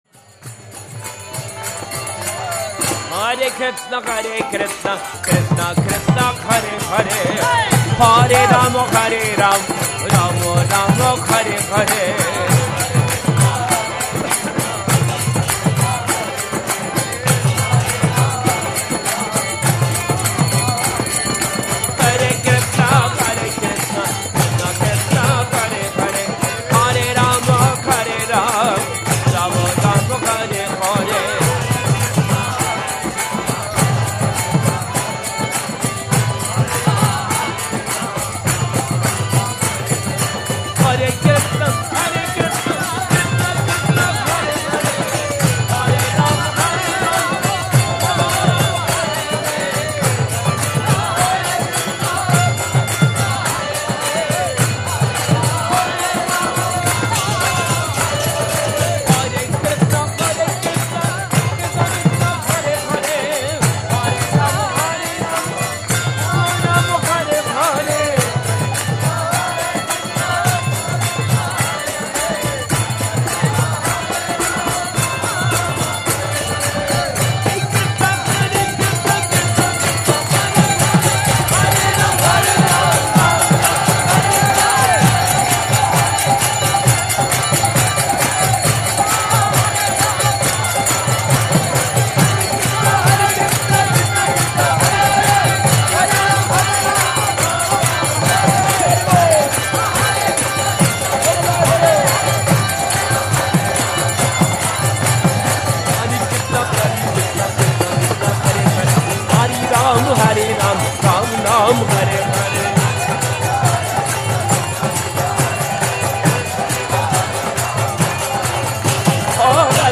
temple kirtans